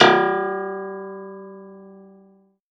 53v-pno01-G1.wav